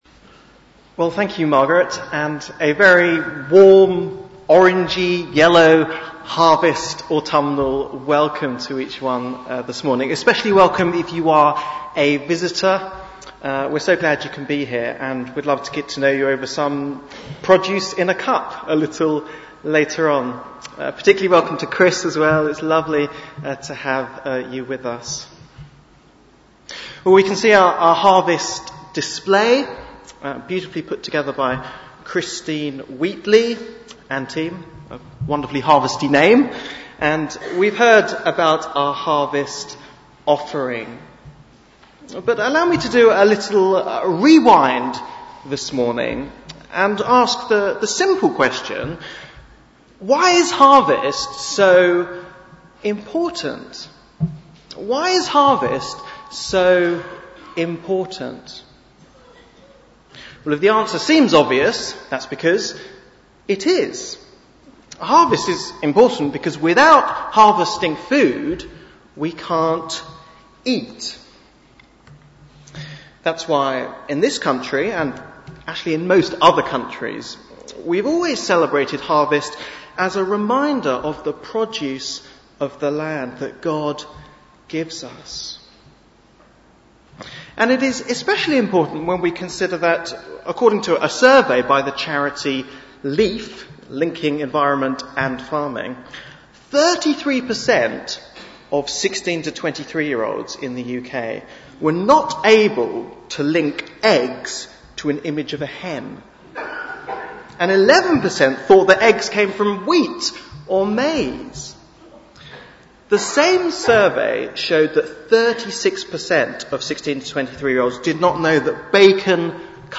Bible Text: Matthew 13:24-30 & 36-43 | Preacher